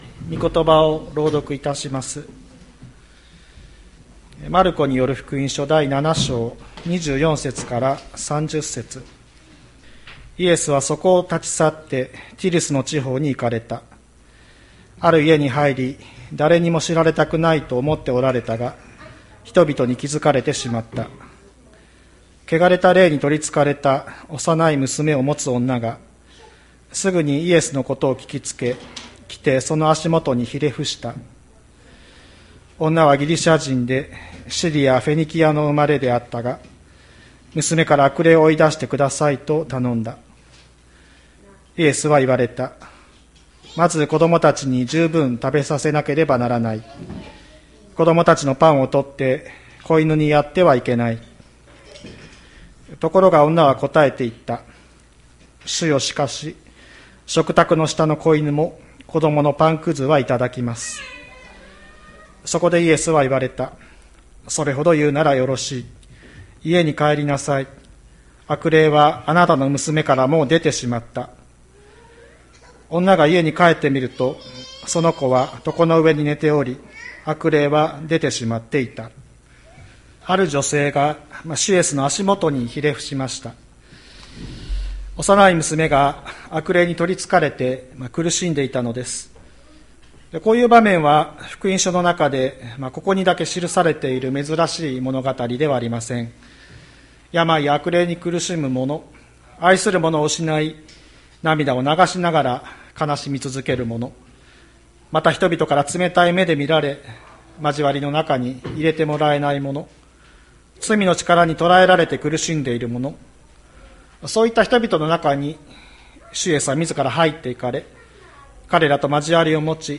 2022年02月20日朝の礼拝「恵みの豊かさを見抜く」吹田市千里山のキリスト教会
千里山教会 2022年02月20日の礼拝メッセージ。